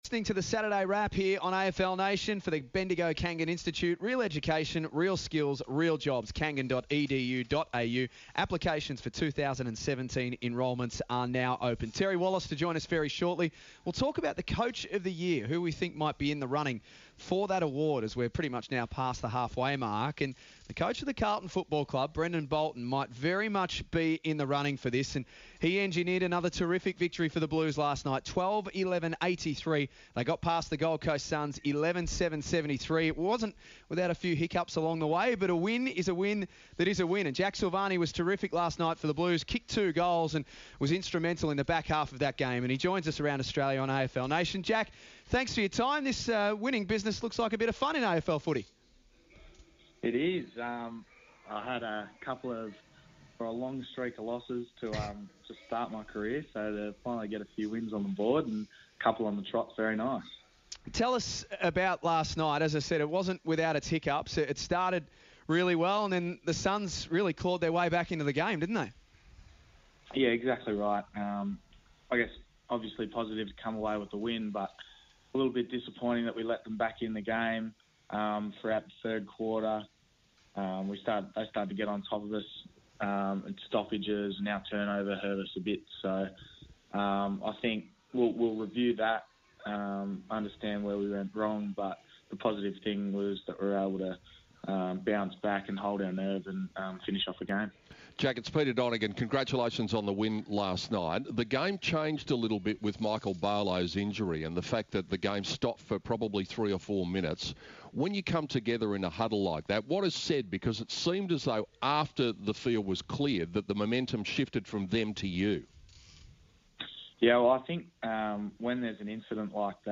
Young Carlton forward Jack Silvagni speaks to AFL Nation after his impressive performance against the Suns.